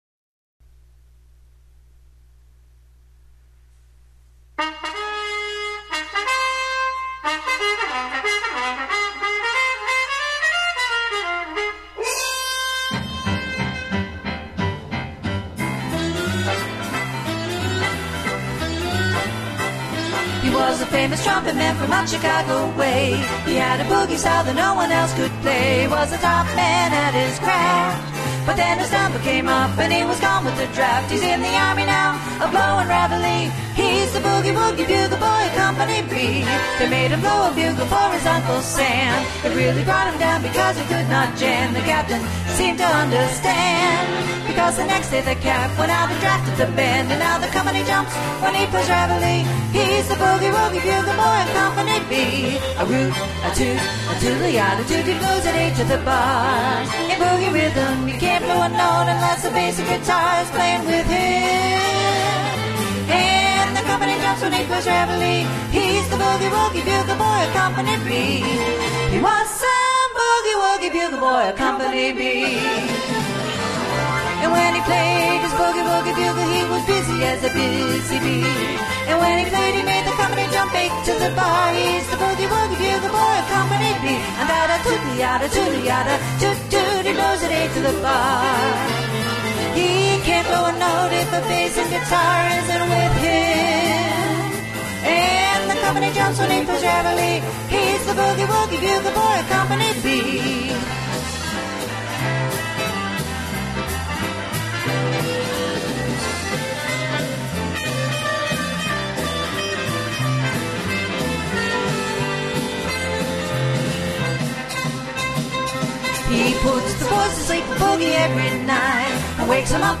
NEW KARAOKE SONGS